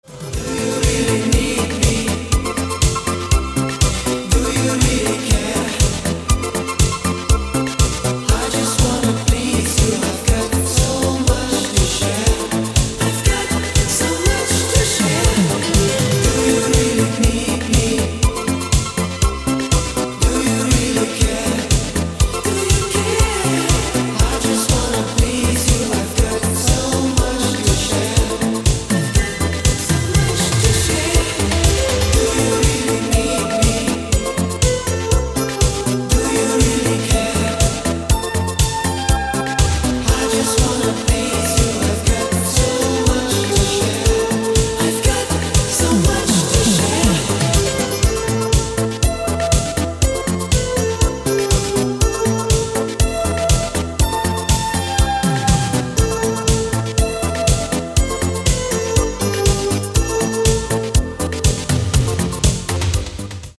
• Качество: 192, Stereo
поп
диско
dance
спокойные
дискотека 80-х
итало-диско